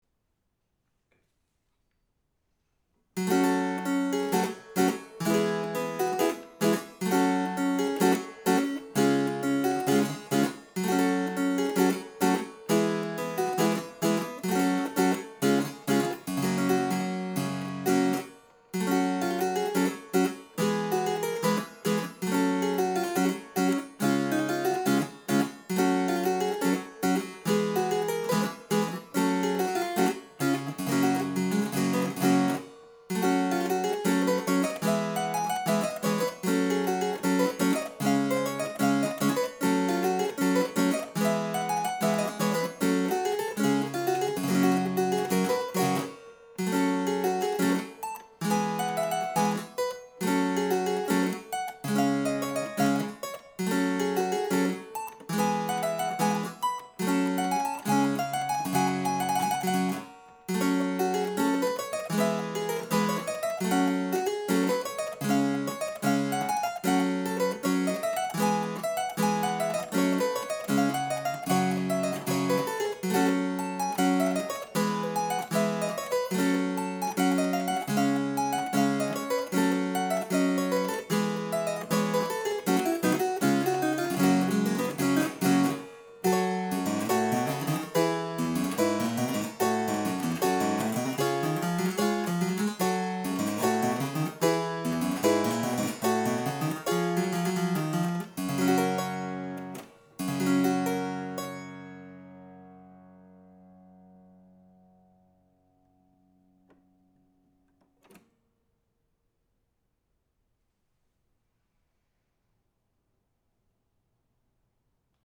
ITALIAN VIRGINALS - The Cobbe Collection
The polygonal virginals by Annibale dei Rossi, Milan (active 1560-1577, d. 1577-1590) or his son Ferrante.